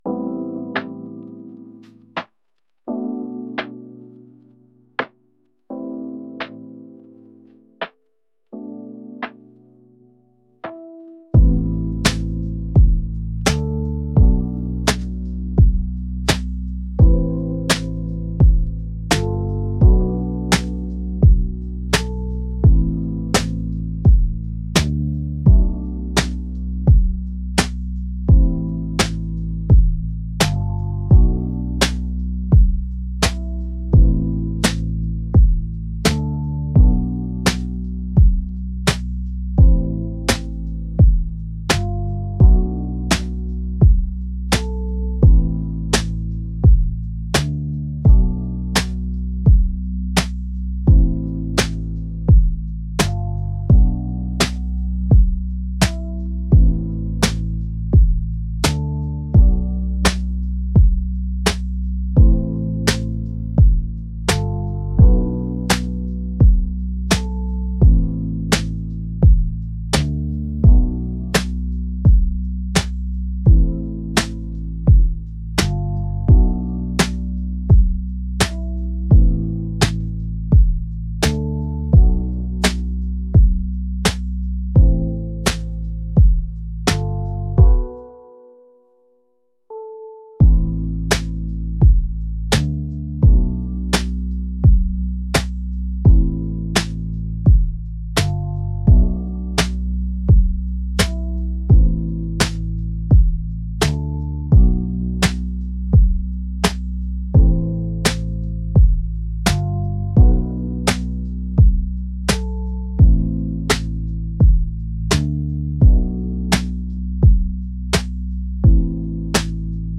mellow